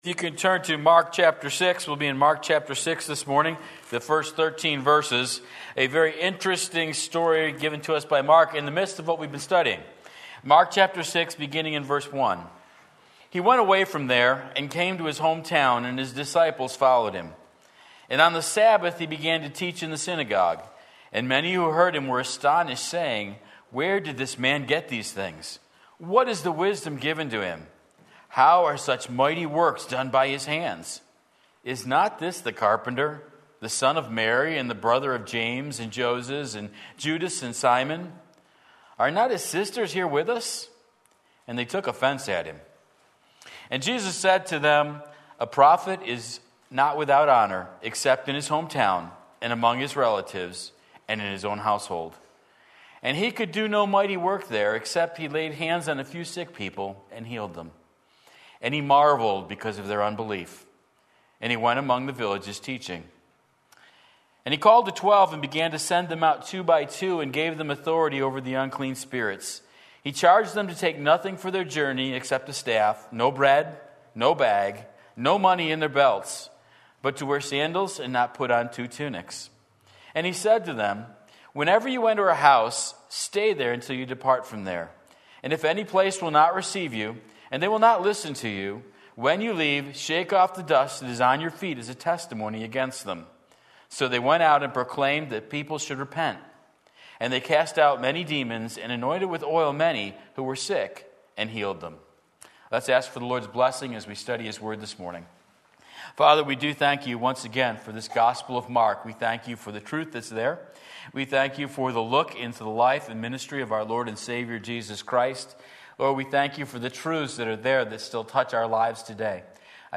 Sermon Link
Astonishing Unbelief Mark 6:1-13 Sunday Morning Service